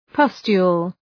Προφορά
{‘pʌstju:l}